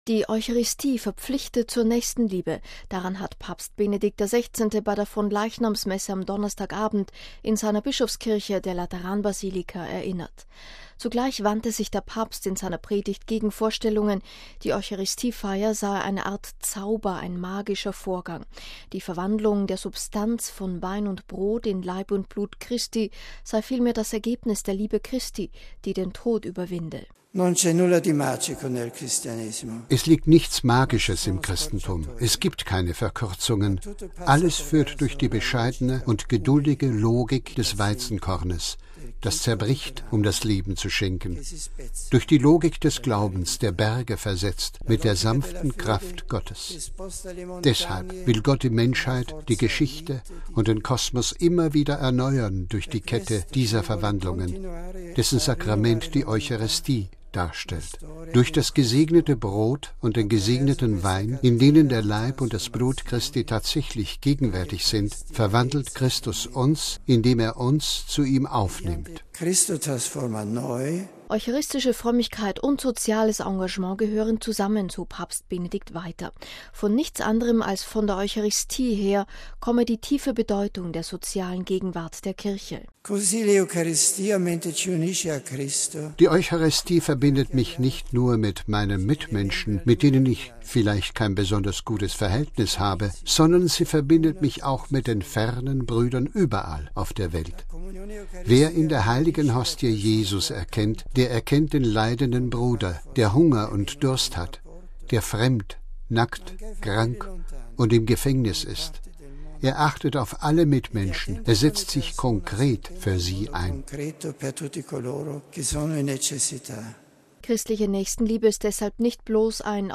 Daran hat Papst Benedikt XVI. bei der Fronleichnamsmesse am Donnerstagabend in seiner Bischofskirche, der Lateranbasilika, erinnert. Zugleich wandte sich der Papst in seiner Predigt gegen Vorstellungen, die Eucharistiefeier sei eine Art Zauber, ein magischer Vorgang.